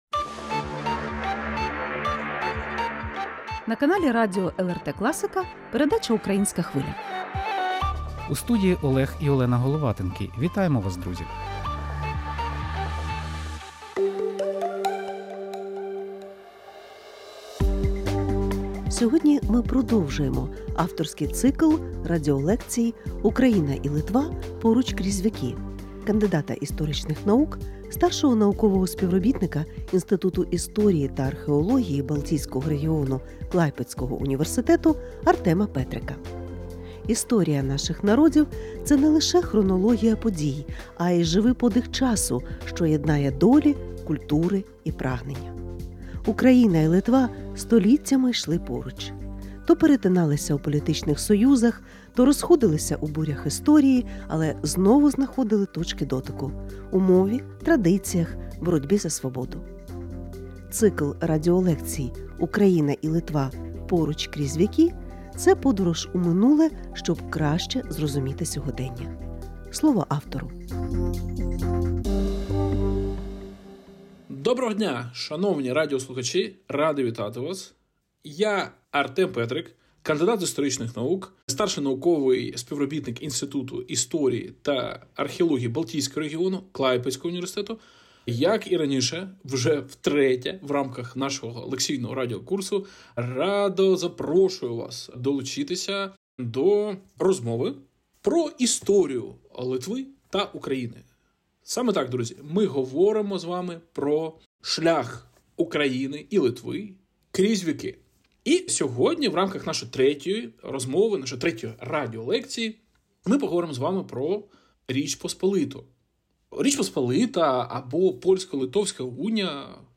Україна і Литва: поруч крізь віки. Радіолекція